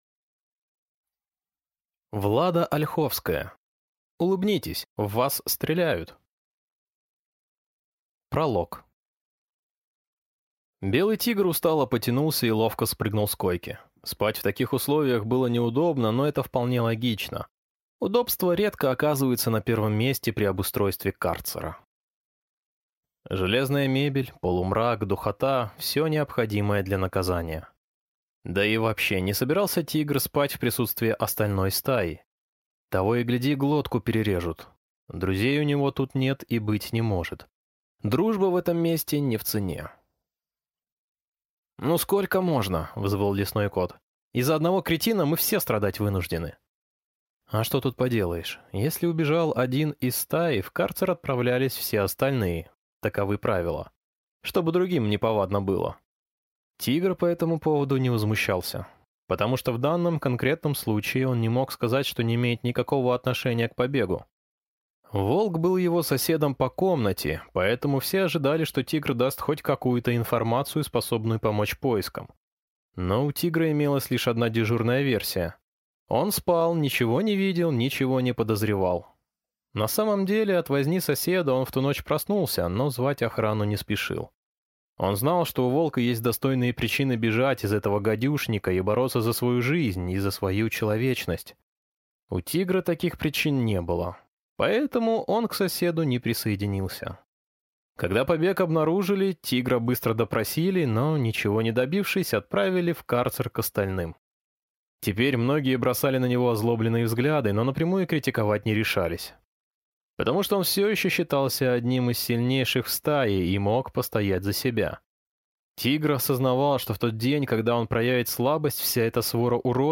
Aудиокнига Улыбнитесь, в вас стреляют!